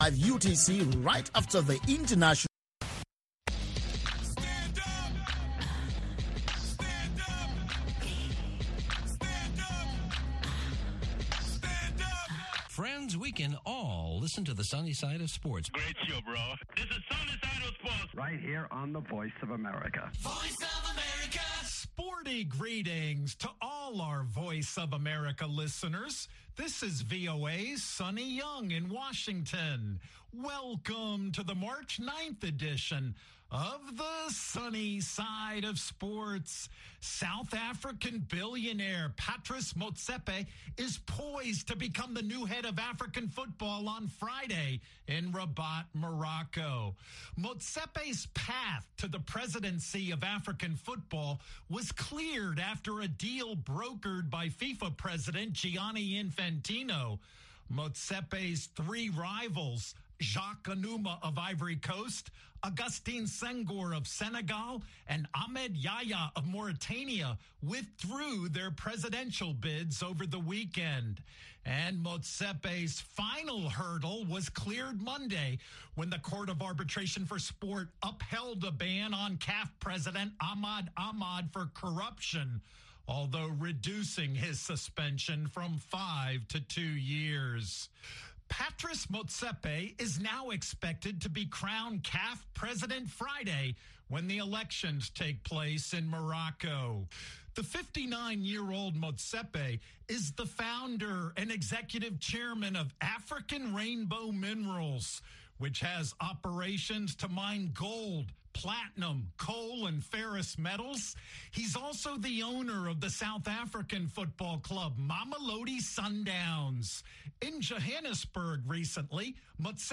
Health Chat is a live call-in program that addresses health issues of interest to Africa.